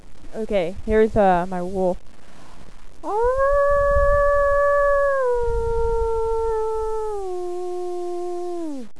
Here are a list of Accents and Impersonations I can do...
Wolf Howl